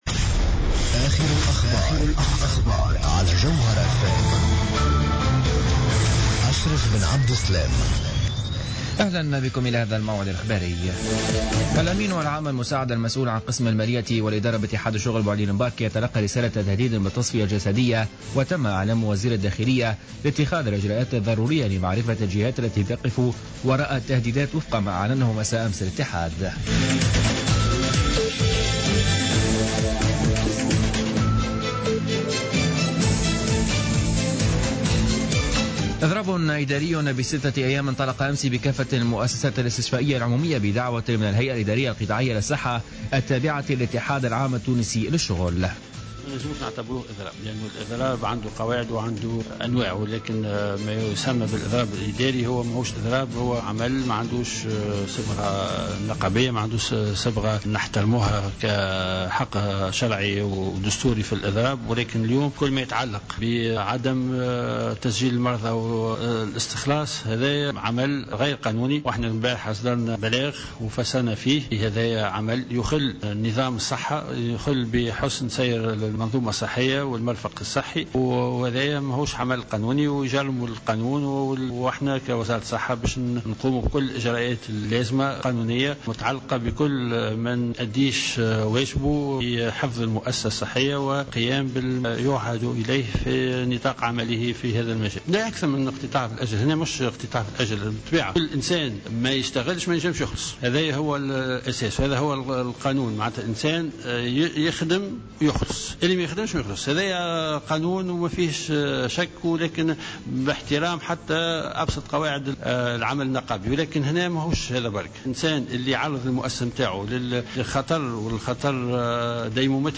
نشرة أخبار منتصف الليل ليوم الثلاثاء 23 جوان 2015